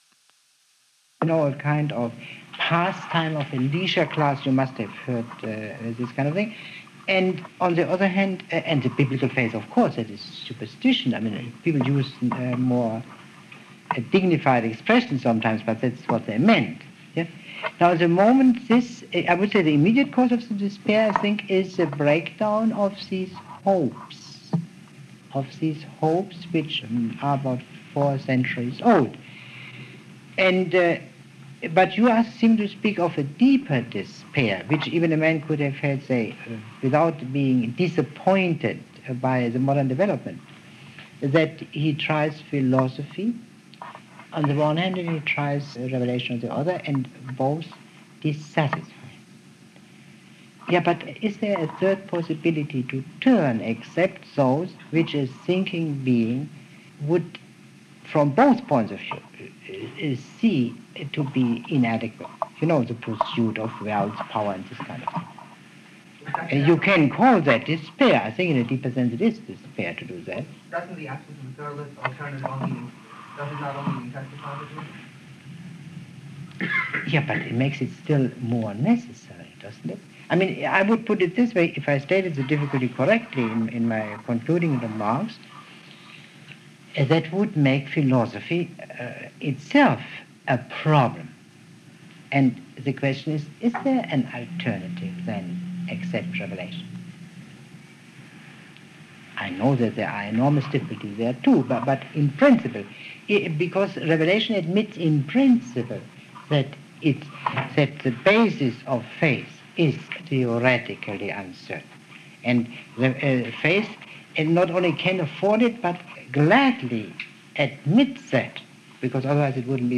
A lecture, the first of three on the topic “Progress or Return?,” given by Leo Strauss at the Hillel Foundation at the University of Chicago on November 5, 1952.